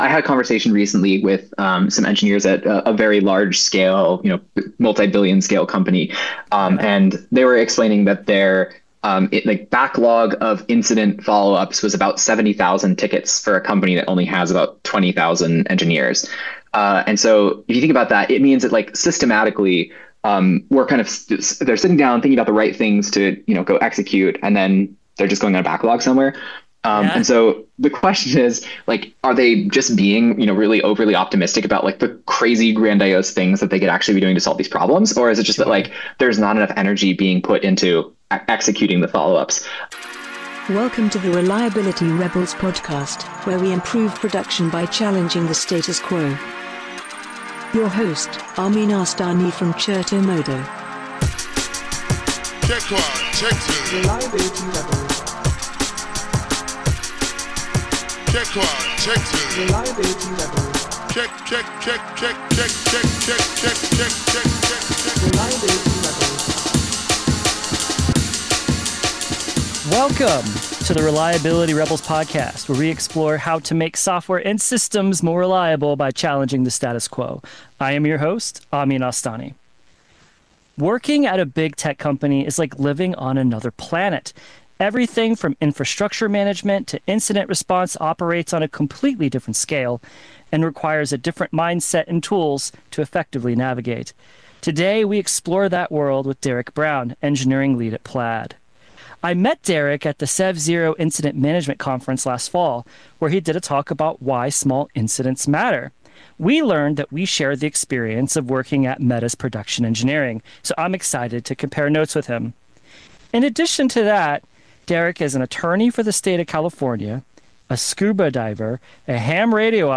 and a drummer- so tune into this really fun conversation!